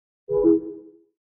Звук обрыва видеосвязи в FaceTime